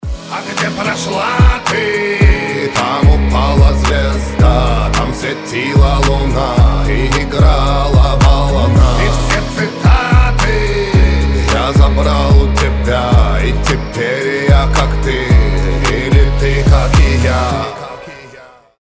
• Качество: 320, Stereo
низкий мужской голос